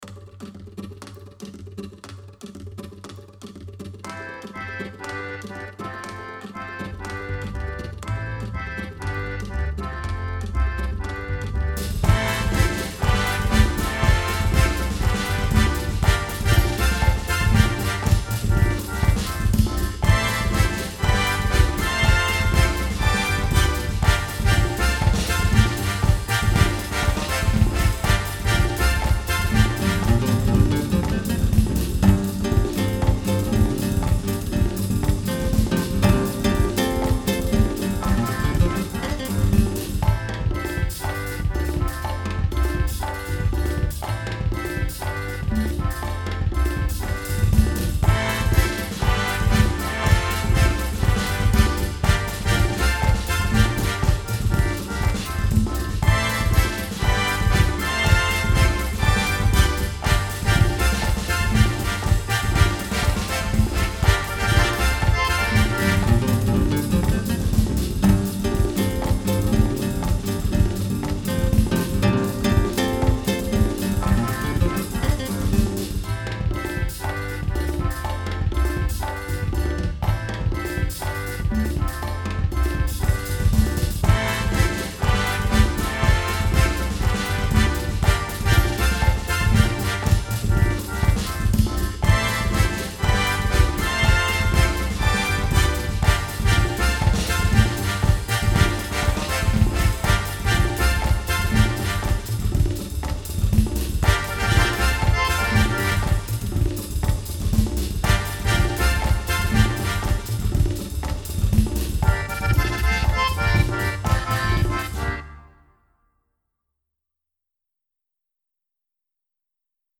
samba style